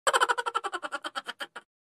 funny-laugh-sound-effect-for-your-vlog.mp3